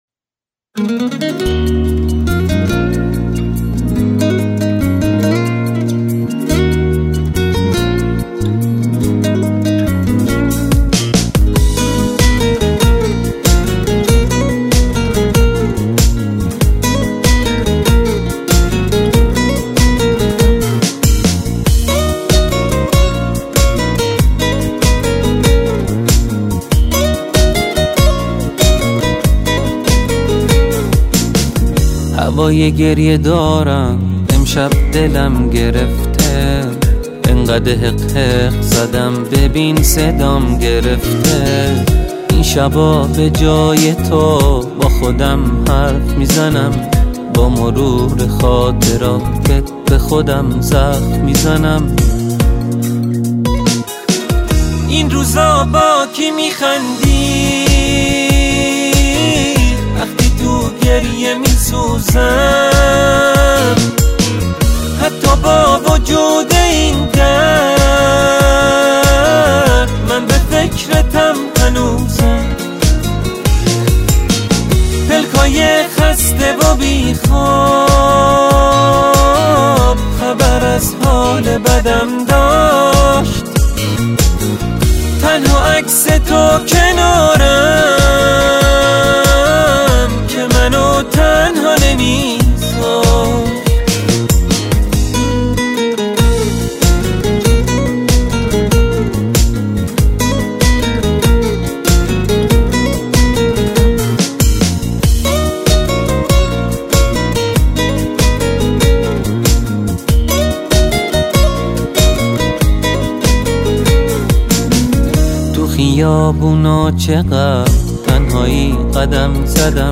• دسته بندی ایرانی پاپ